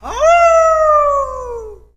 leon_wolf_ulti_vo_02.ogg